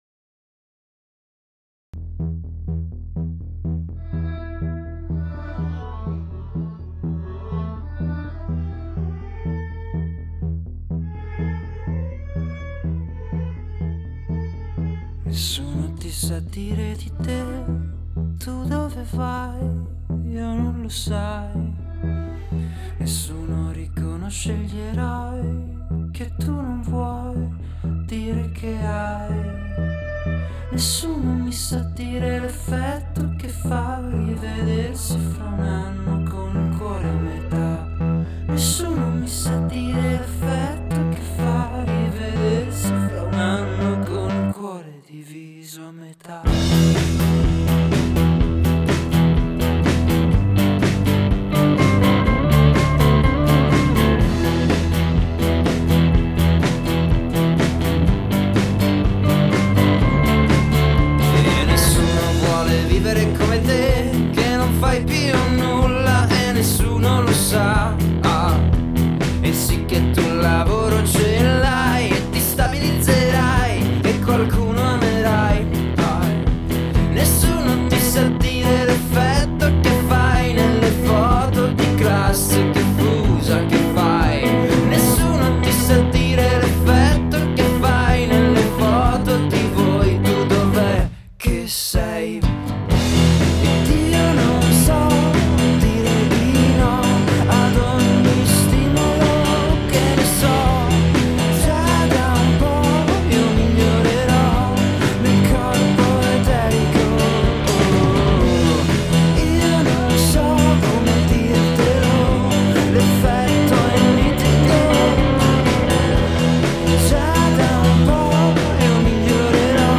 psych pop/rock
al muro sonoro chitarra / batteria
sonorità elettroniche e synth